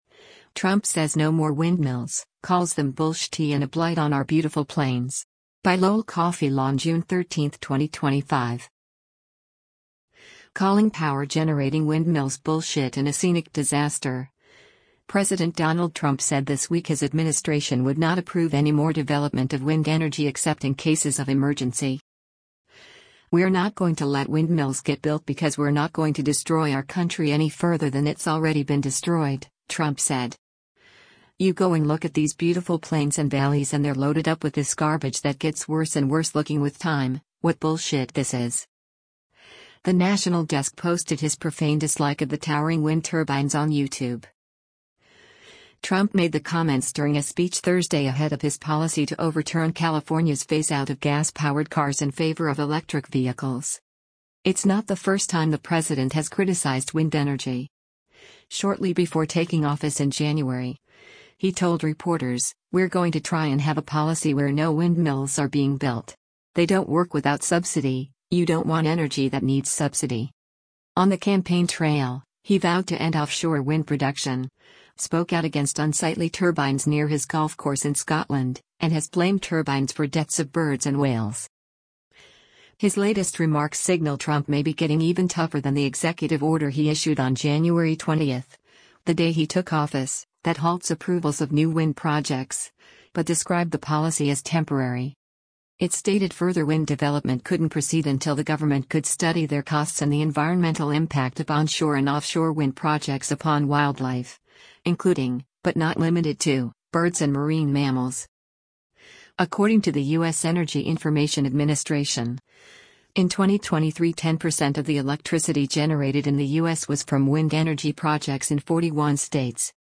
Trump made the comments during a speech Thursday ahead of his policy to overturn California’s phaseout of gas-powered cars in favor of electric vehicles.